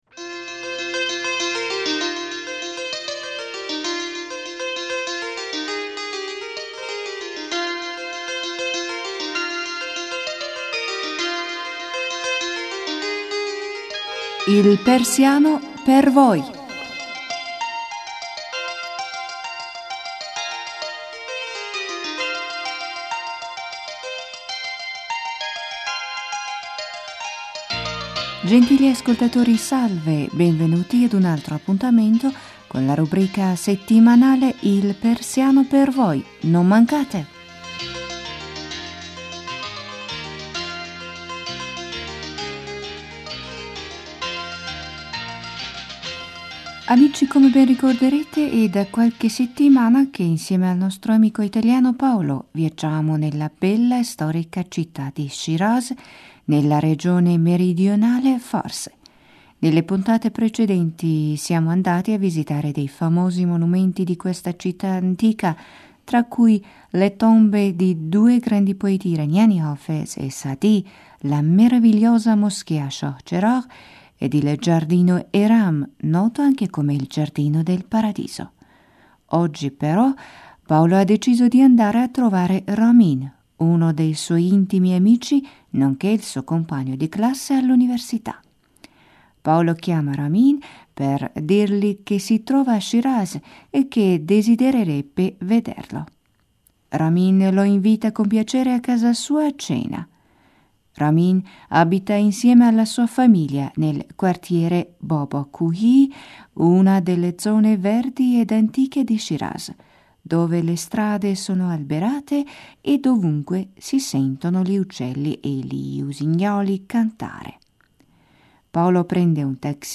Salve gentili ascoltatori e beventui ad una altra puntata della rappresentazione radiofonica dalla “Culla dell’Islam”. Nelle varie storie che vi riporteremo, conoscerete la straordinaria personalità di colui che divenne l’ultimo Messaggero di Dio, sin dall’infanzia, adolescenza, gioventù e anche gli anni in cui l’impero islamico divenne una delle potenze più grandi del mondo.